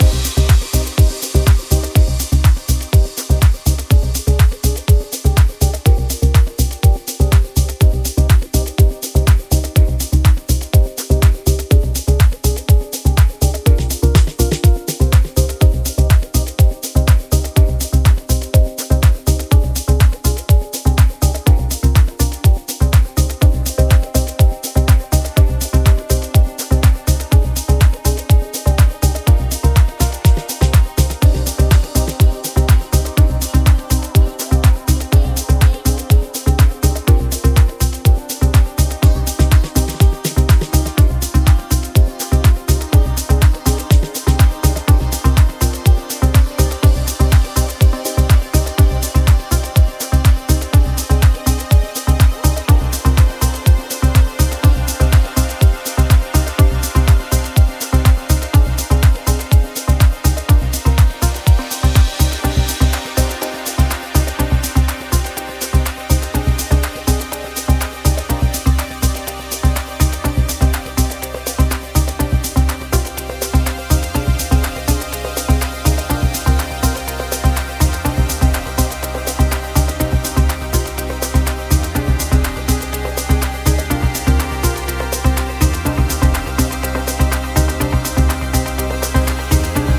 中東のメロディックなフレーバーにスパイスを効かせたパーカッシブなトラックで、メロディック・ハウス＆テクノを展開。
ジャンル(スタイル) HOUSE / TECHNO